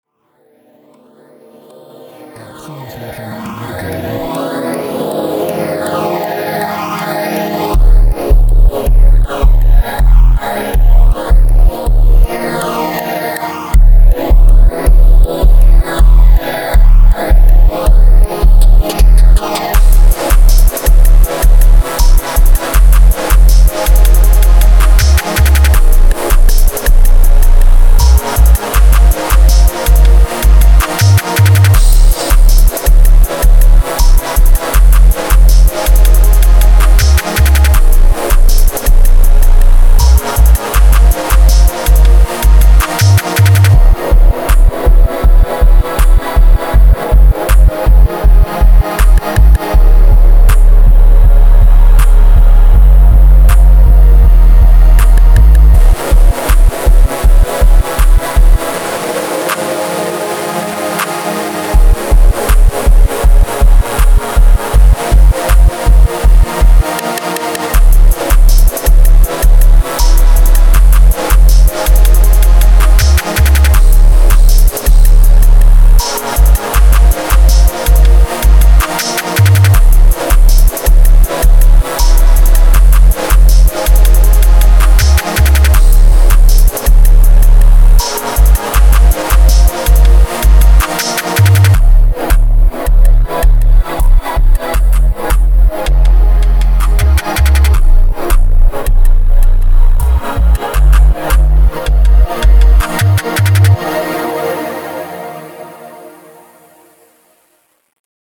Trap Динамичный 160 BPM